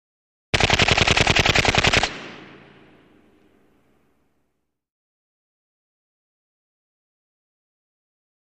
9 mm UZI Automatic Fire 3; With Ring Off.